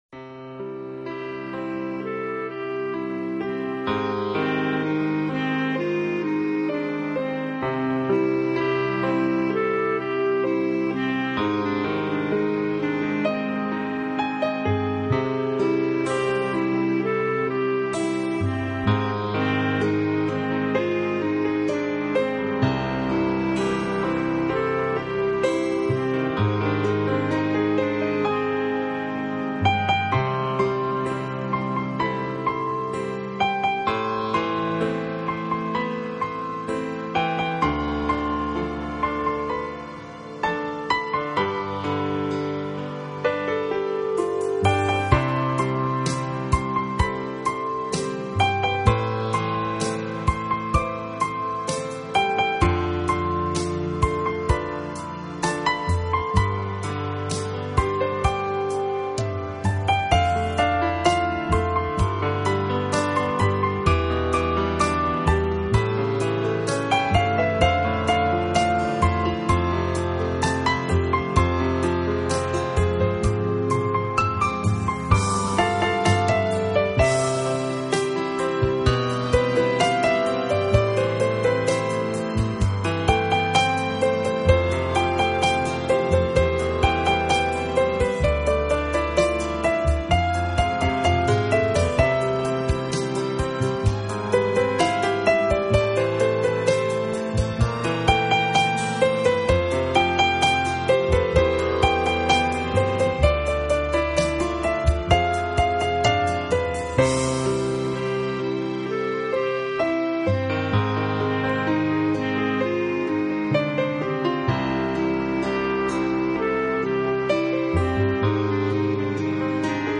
【浪漫钢琴】
这是一套非常经典的老曲目经过改编用钢琴重新演绎的系列专辑。
本套CD全部钢琴演奏，